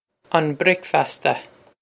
Pronunciation for how to say
On brik-fass-ta
This is an approximate phonetic pronunciation of the phrase.